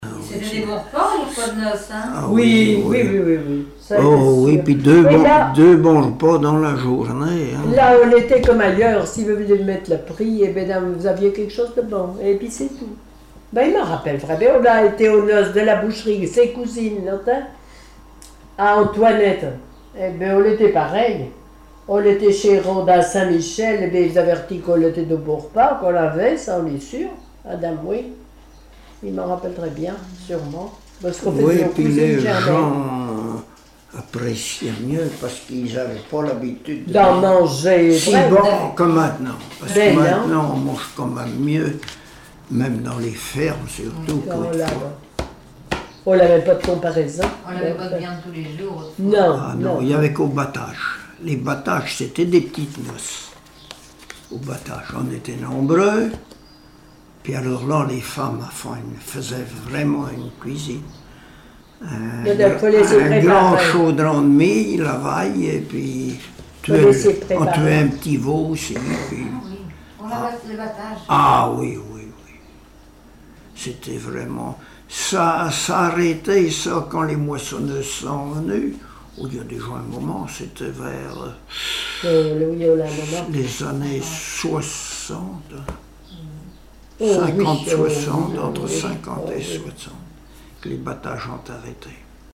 Enquête Arexcpo en Vendée-Association Joyeux Vendéens
Catégorie Témoignage